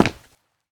scpcb-godot/SFX/Step/Run5.ogg at 59a9ac02fec0c26d3f2b1135b8e2b2ea652d5ff6